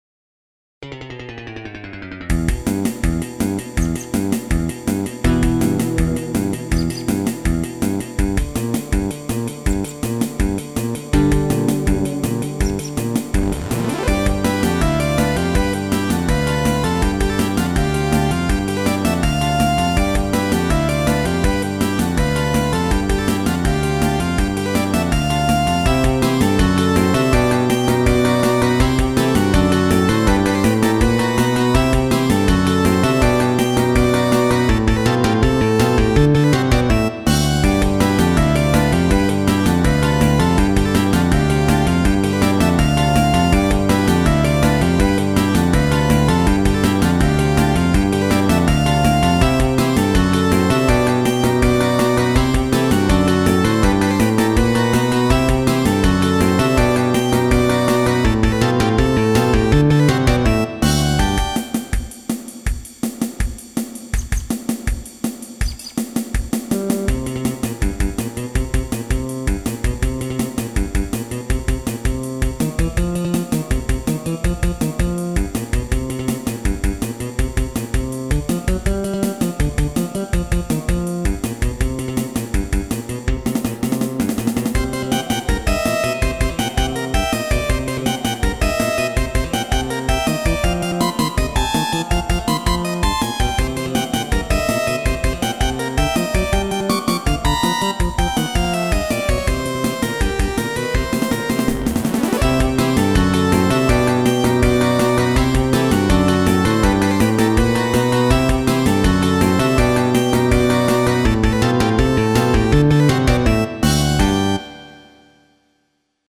唯一この曲だけテンポが最初から終わりまで原曲と同時再生して見事に一致しています。